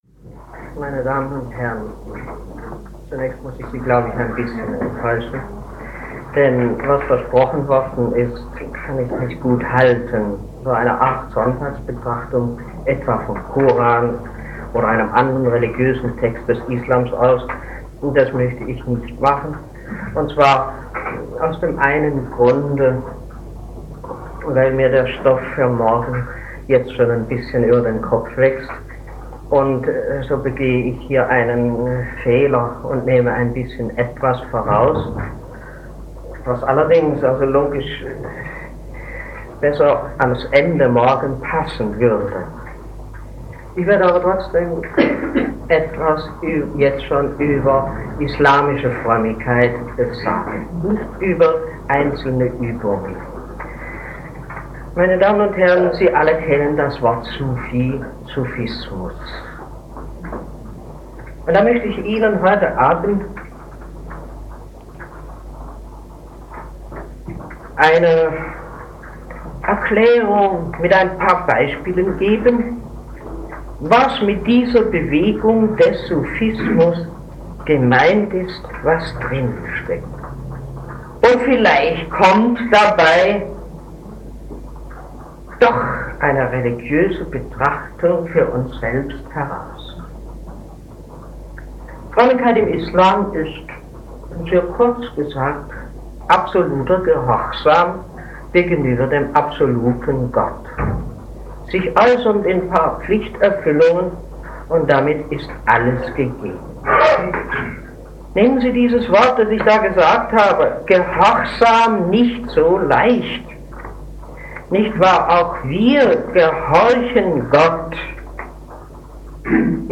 Frömmigkeit des Islam (Sufismus) - Rede des Monats - Religion und Theologie - Religion und Theologie - Kategorien - Videoportal Universität Freiburg